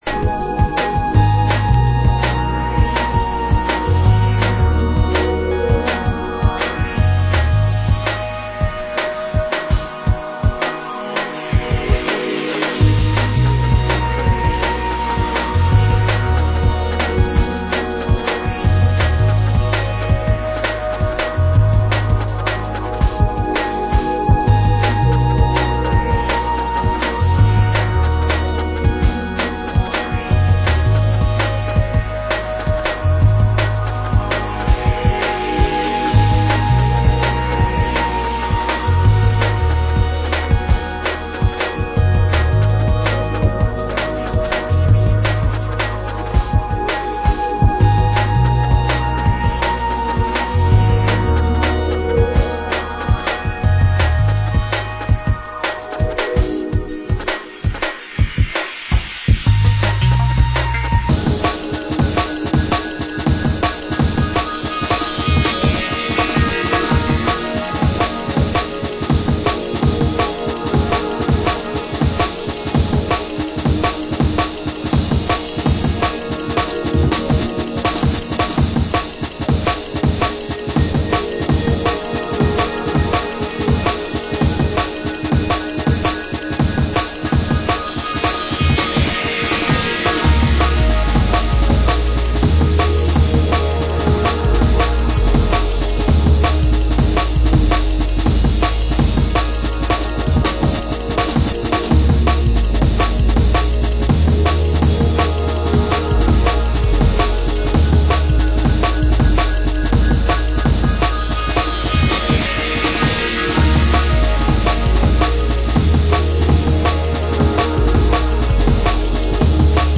Genre Drum & Bass